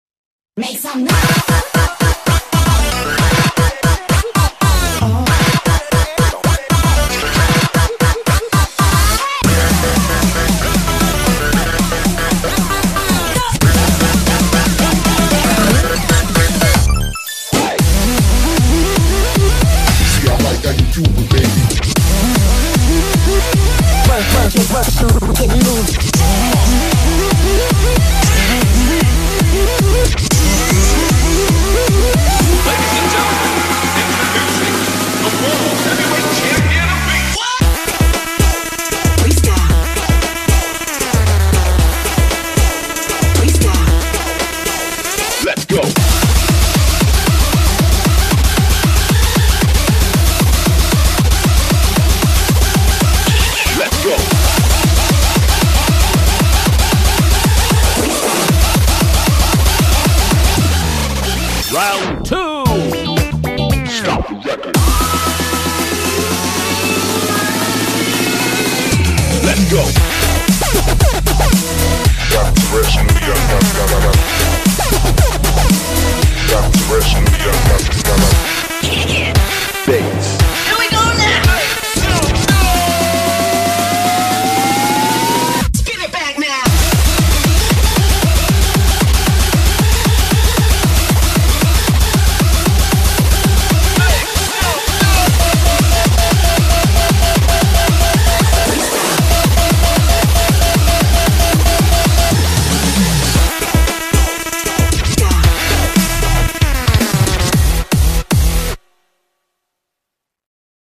BPM115-230
Audio QualityPerfect (Low Quality)